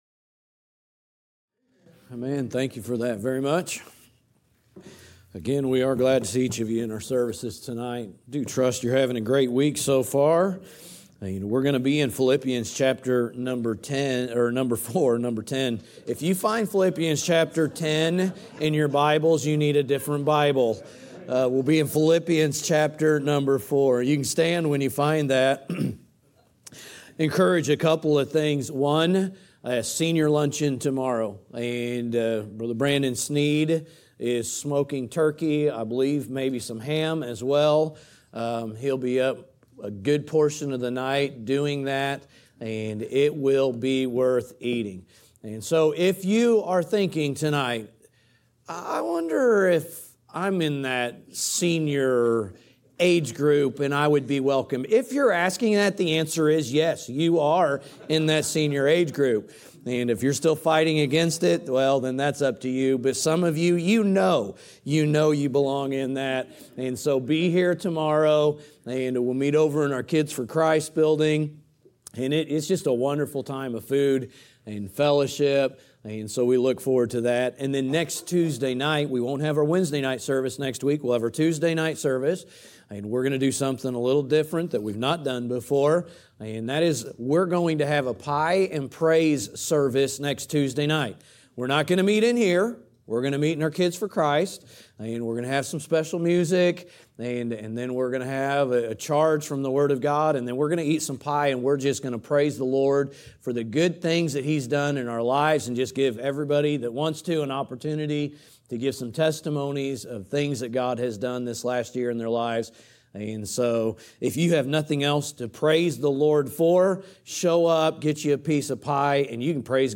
preaching through the book of Philippians